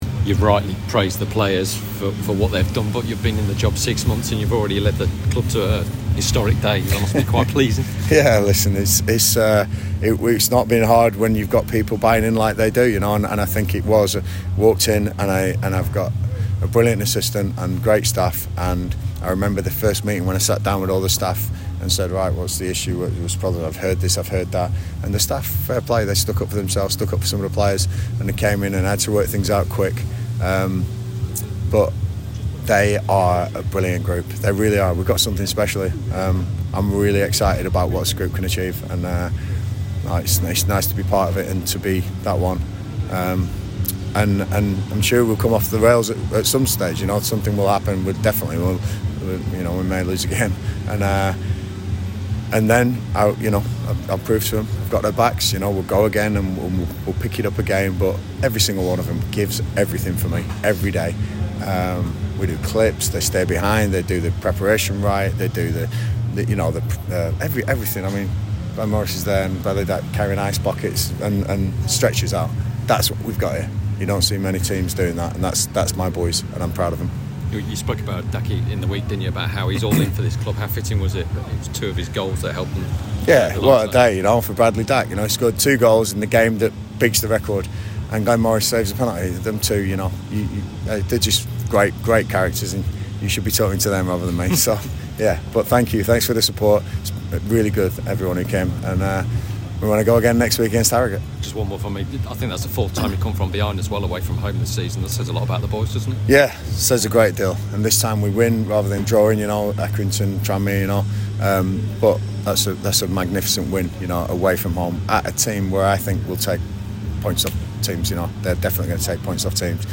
Listen: Gillingham manager Gareth Ainsworth reacts to their 3-1 win over Newport County - 21/09/2025